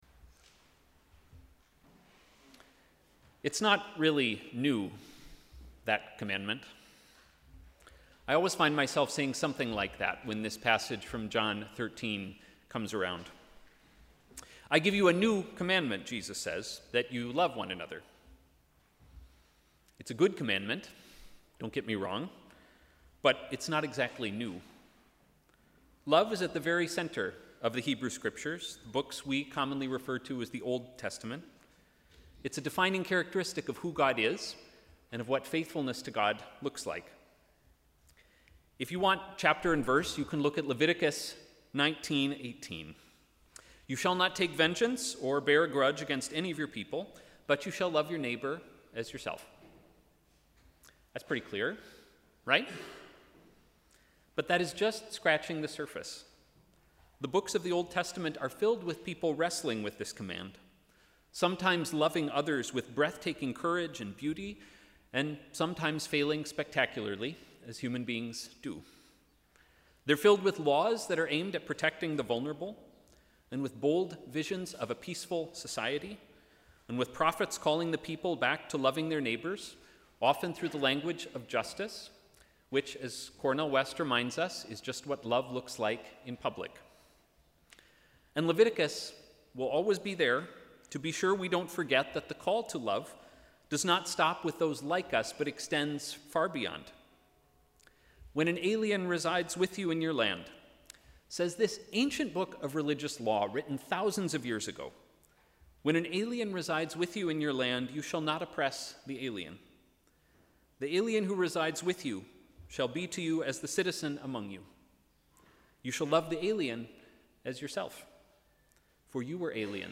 Sermon: ‘Acts of love’